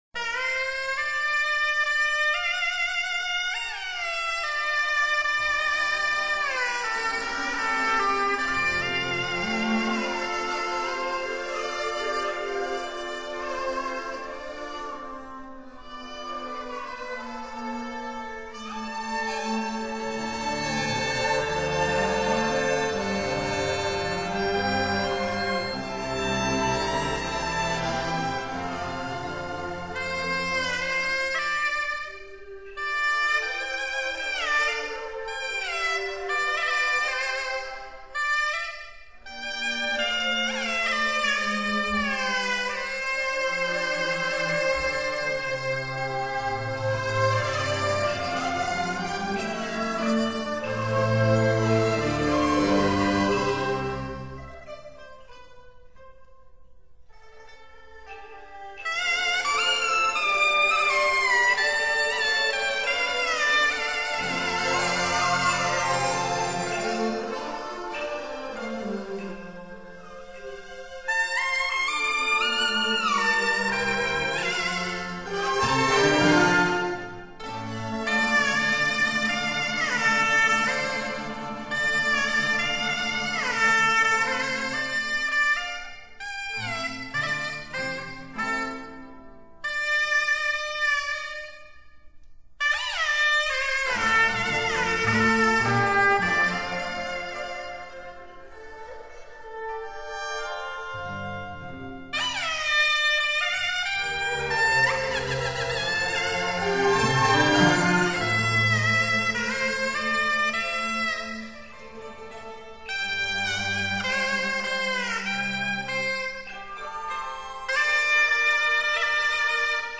当代民族交响乐优秀作品